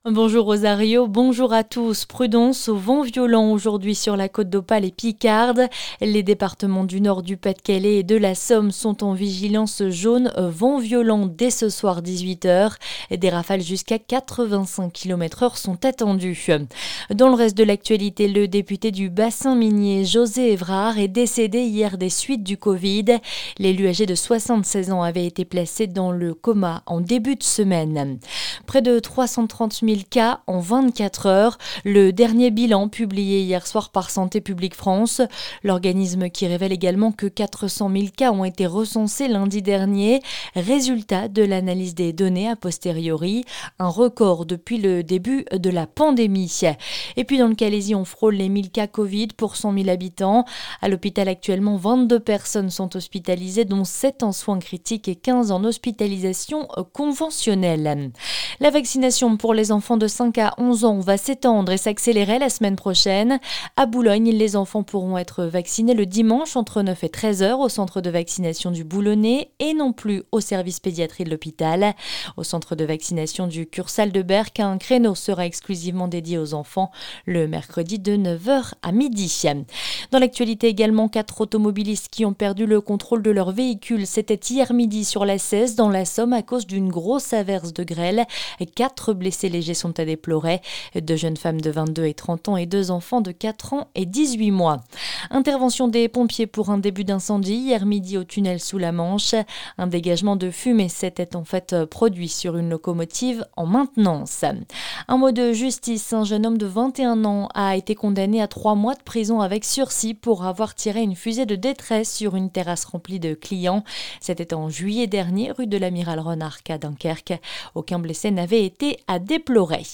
Le journal Côte d'Opale et Côte Picarde du samedi 8 janvier